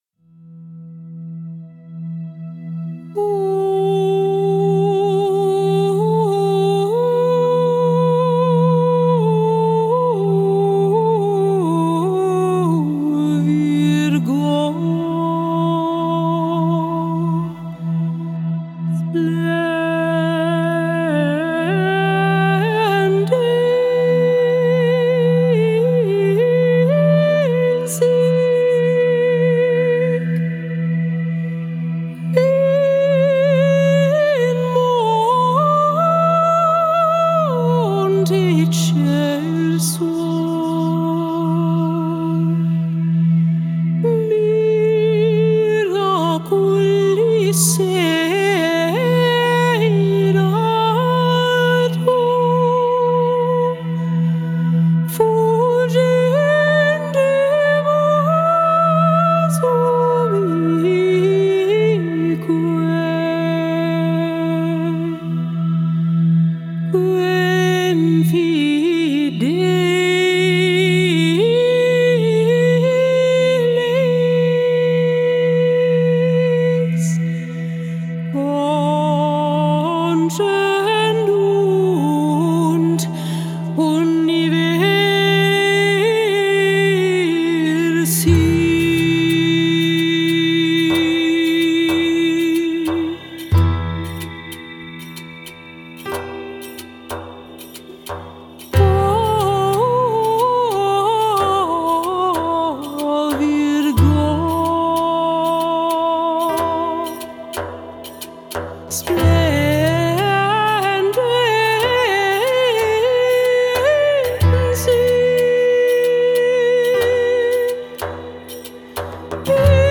Traditional Early music piece
Vocals
Piano
Frame Drum
Riqq